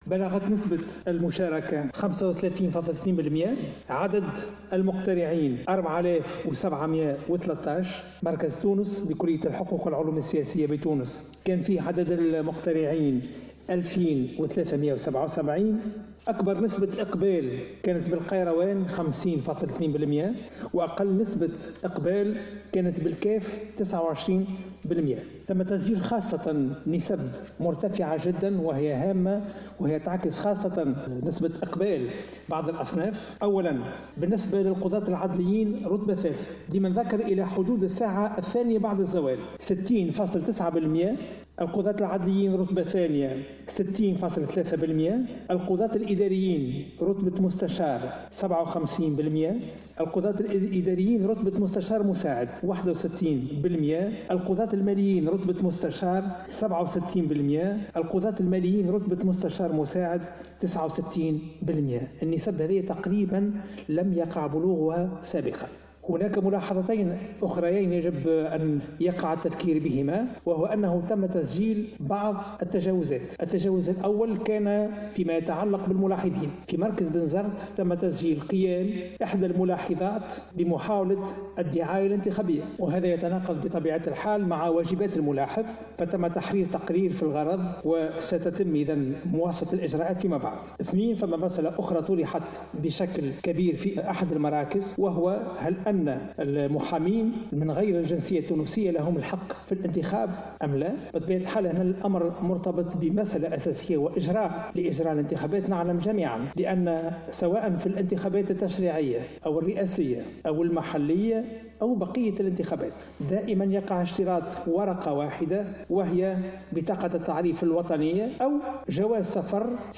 قال شفيق صرصار رئيس الهيئة العليا المستقلة للانتخابات، خلال ندوة صحفية عقدتها الهيئة اليوم الأحد على الساعة الثالثة بعد الظهر بقصر المؤتمرات بالعاصمة، إن نسبة الإقبال على إنتخابات المجلس الأعلى للقضاء بلغت 35.2 بالمائة.